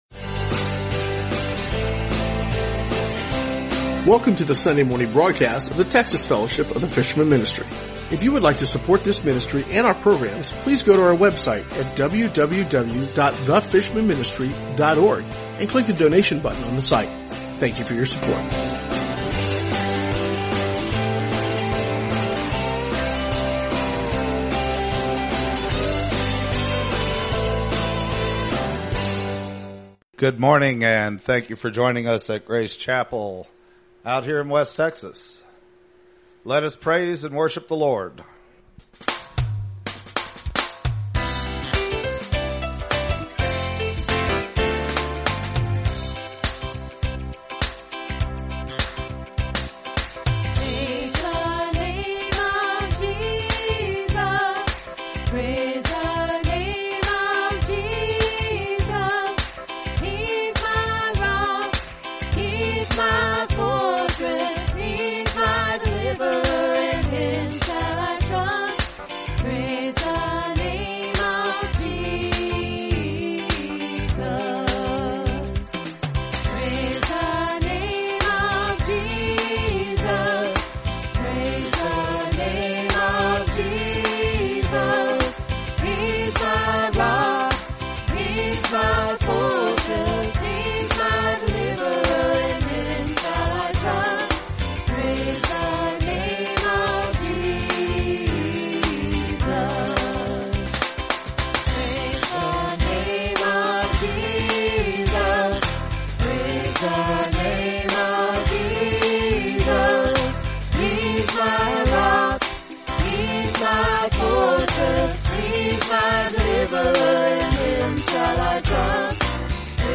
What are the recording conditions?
Sunday Texas Church Service 05/15/2016 | The Fishermen Ministry